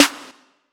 80s_drummachinesnr.wav